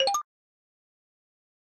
notif.ogg